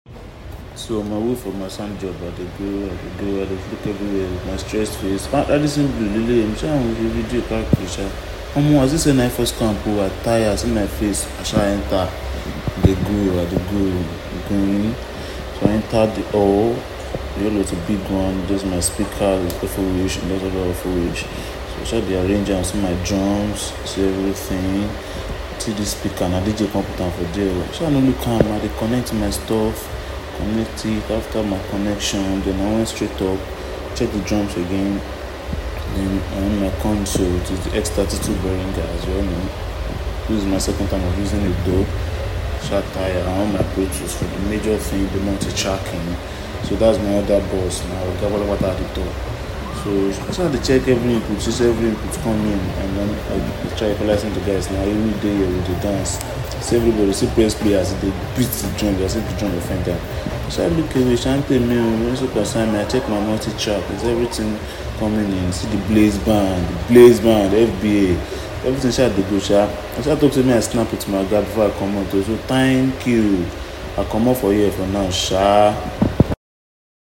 Live sound setup @ VI sound effects free download